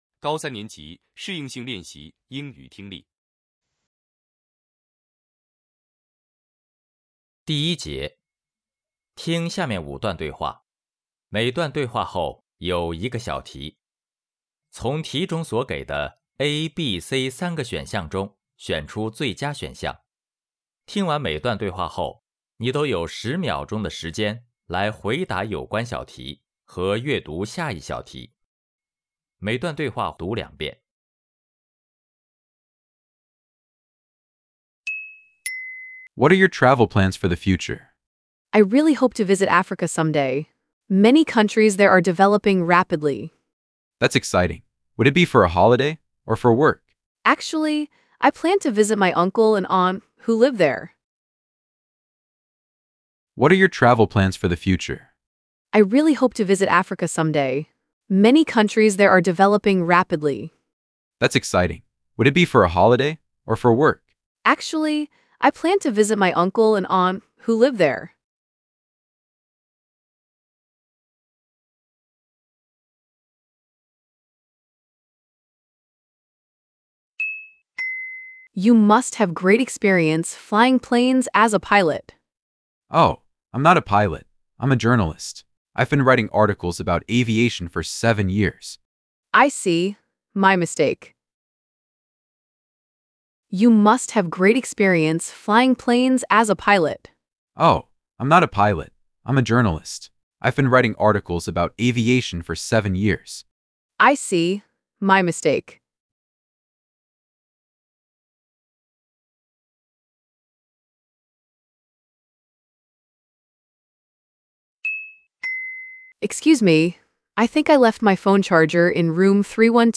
德阳市2026届高三年级适应性练习（德阳三诊）英语听力.mp3